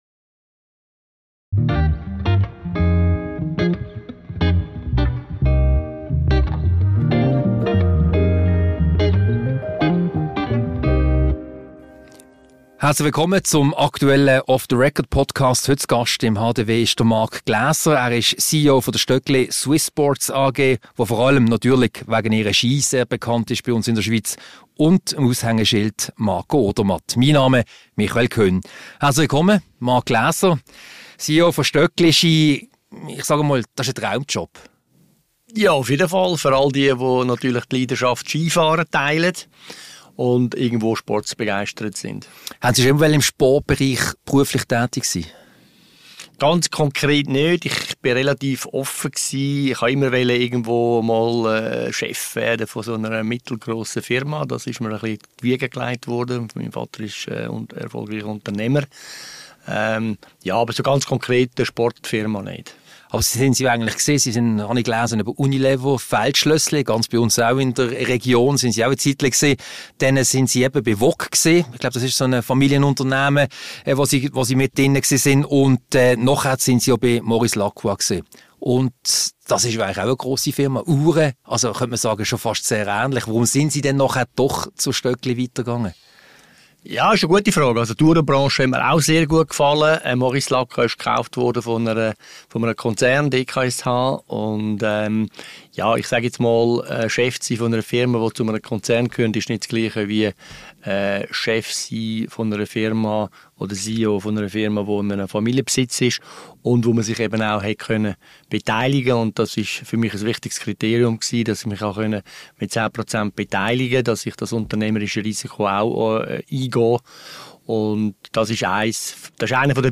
Ein Gespräch über die Stöckli Swiss Sports AG, Swissness, Traumjobs und Marco Odermatt.